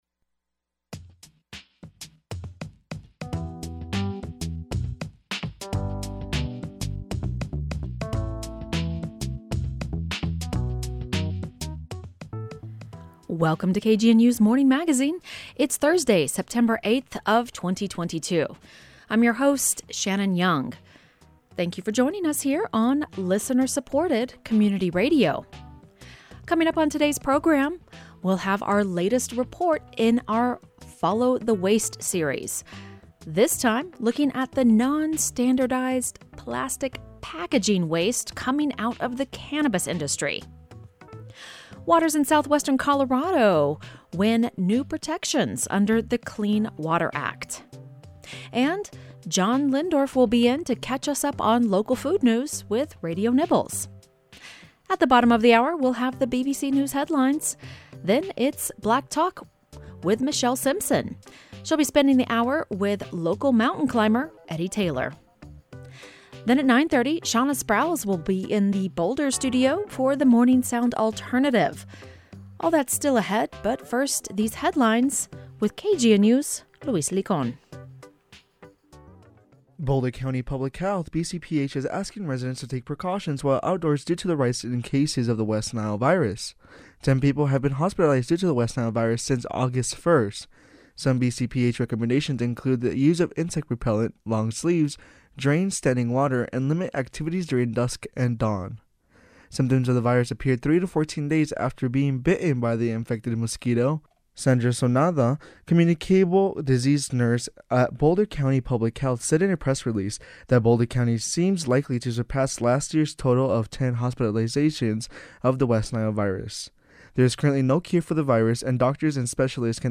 The Morning Magazine features local news headlines, stories, and features and broadcasts on KGNU Monday through Friday, 8.04-8.30 AM.